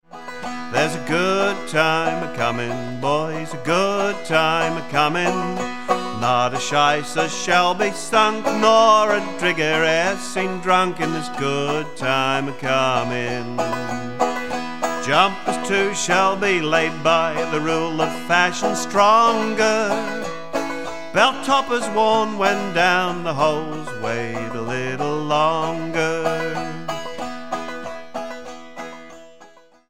"The Good Time Coming" was a song which described how things would eventually get better for miners. The Australian folk singer